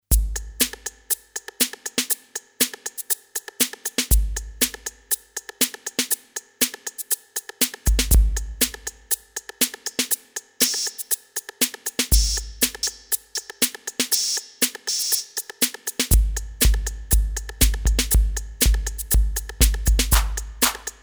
TR808 (or so)